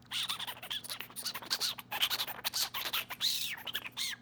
animals